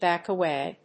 アクセントbáck awáy